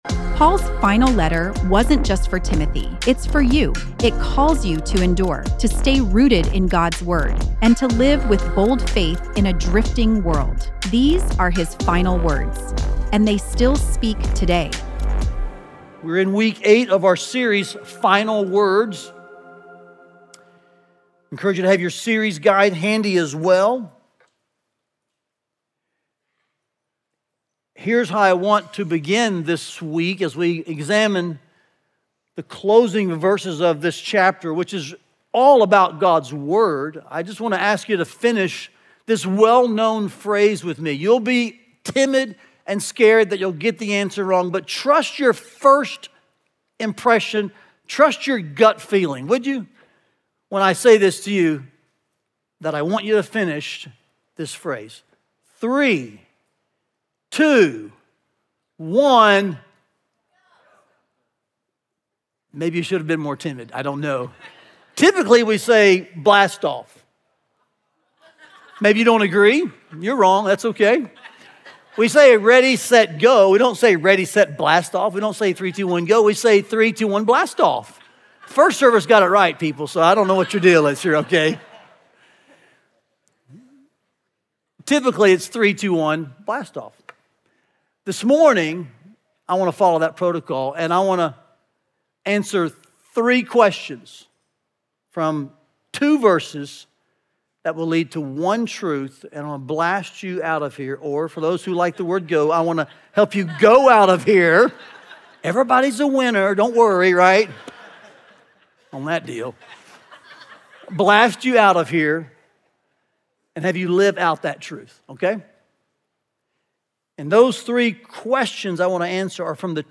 Listen to the latest sermon from our 2 Timothy series, “Final Words”, and learn more about the series here.